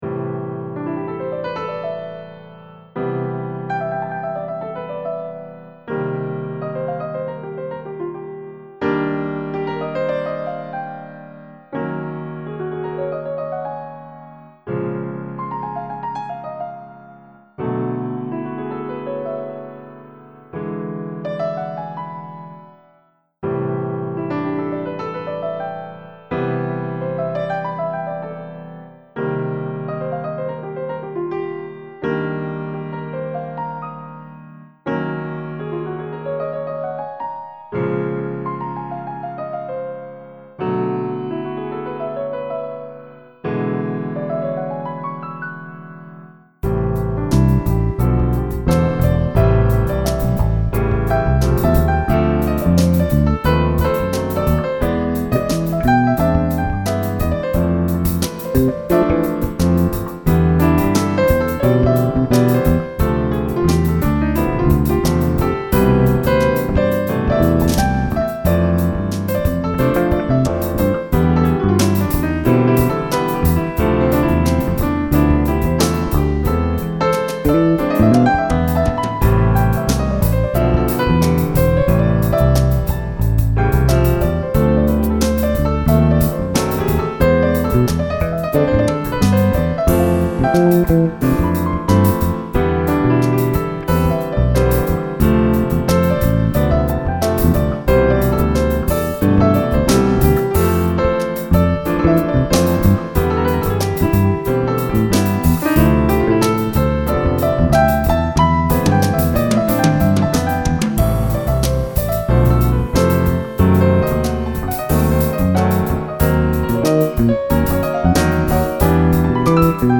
E-guitars and basses went directly into the mixer, acoustic guitars and percussion were recorded with the AKG microphones. Everything else is the sound of virtual instruments, played with the keyboard.
This is not easy listening, not background music, not music for multitaskers.